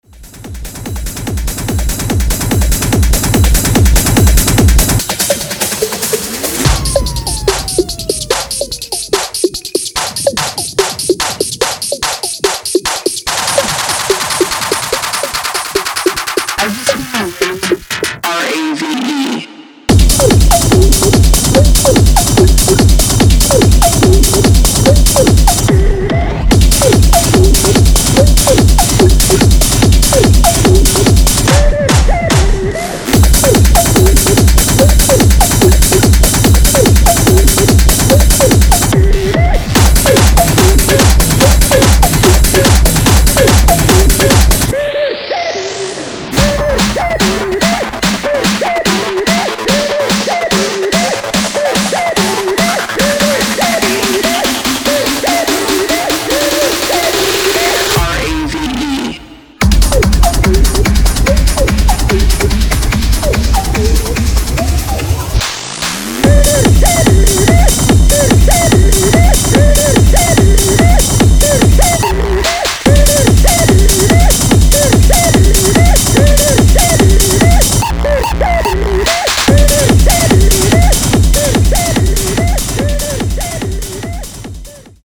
Electronic
Techno, Hard Techno, Hardstyle, Jumpstyle